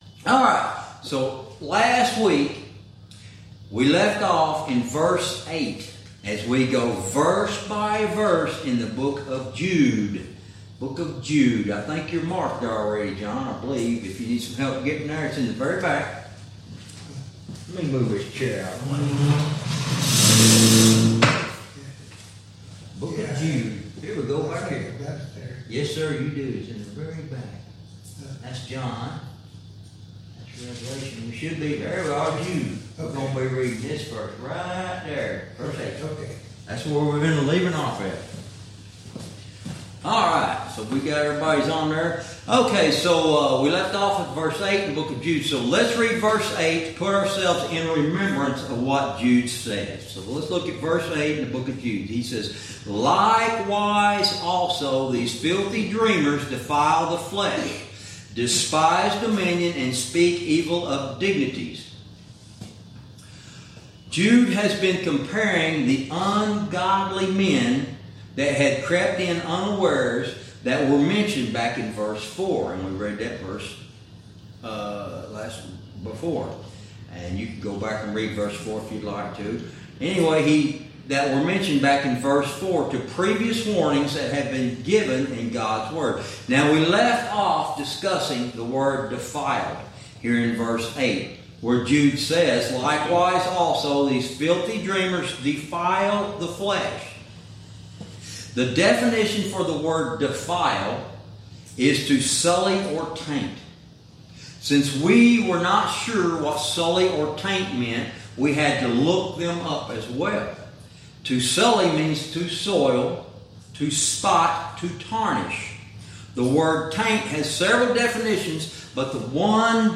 Verse by verse teaching - Lesson 26